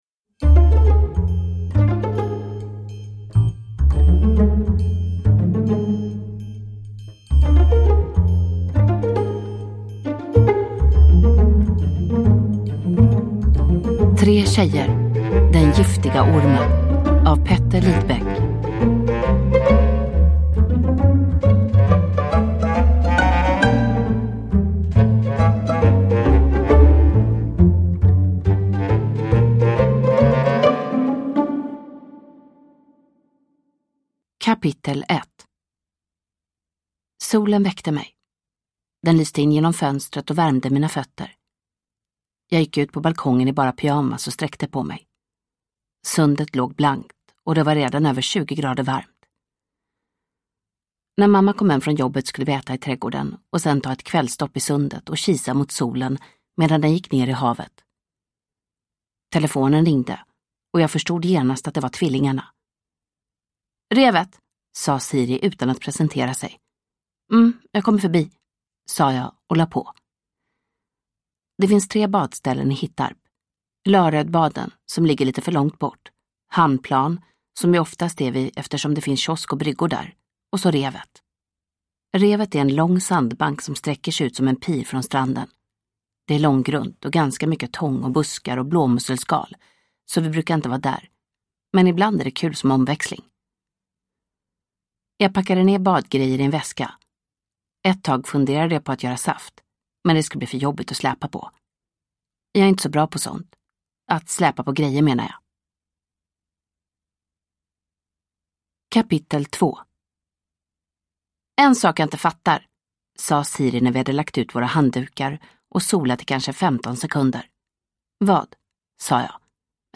Den giftiga ormen – Ljudbok